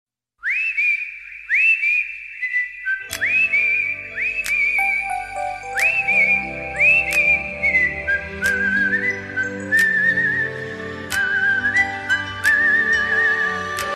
• Качество: 128, Stereo
эхо
лес